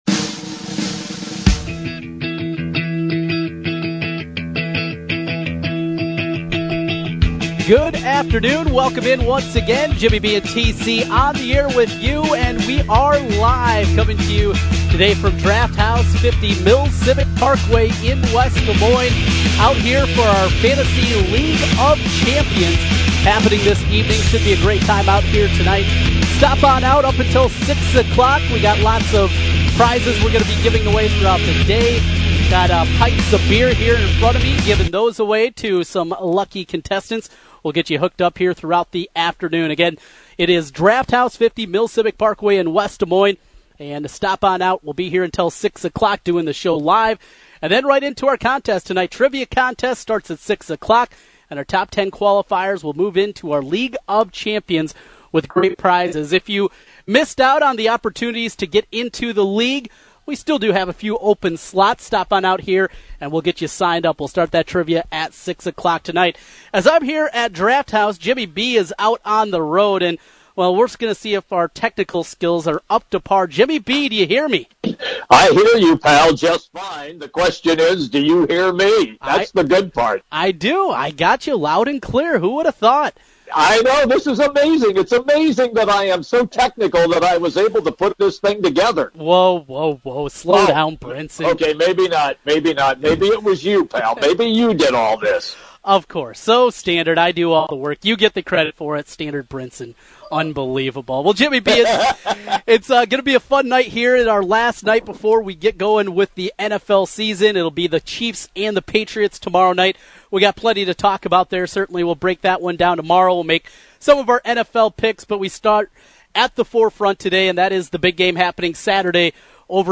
live from Draught House 50